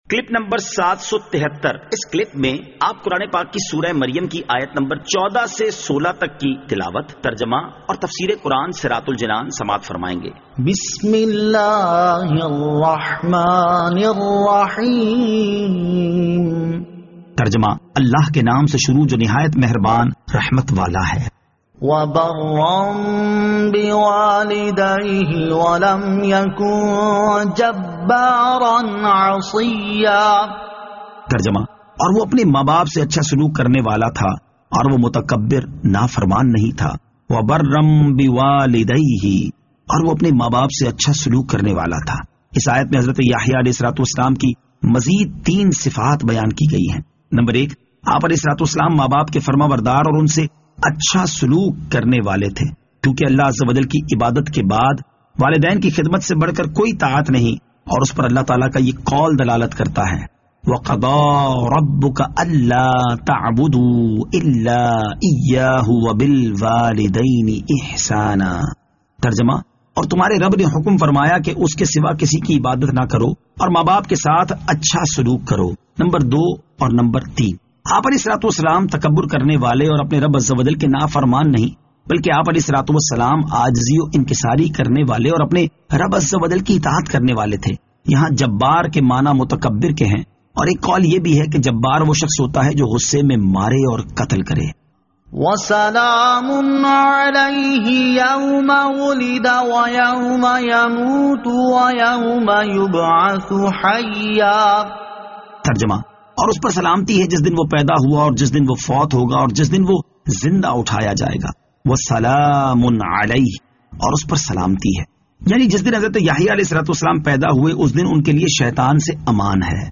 Surah Maryam Ayat 14 To 16 Tilawat , Tarjama , Tafseer